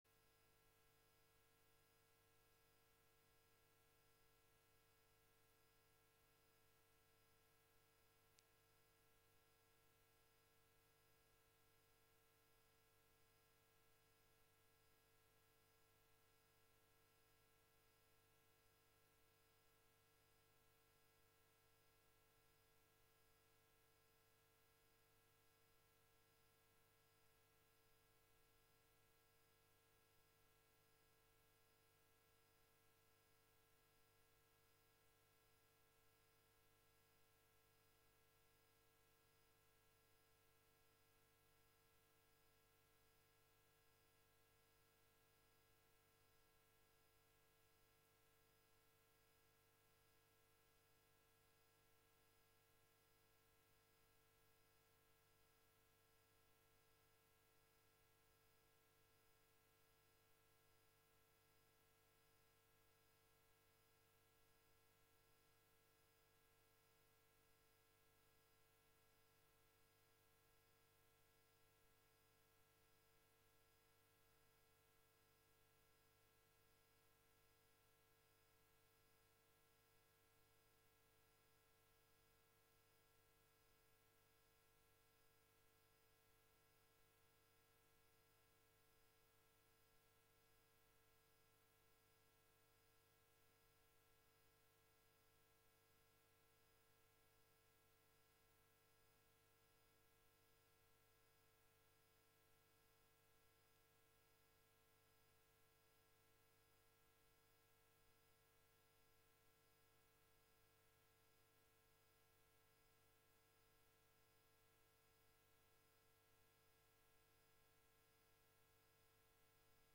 خطبة - أسباب الأنحراف